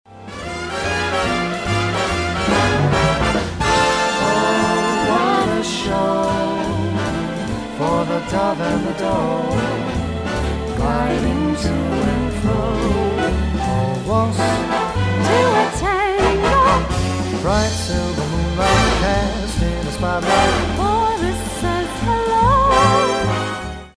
Big Band
Pop Jazz
quarter note = 150
Male & Female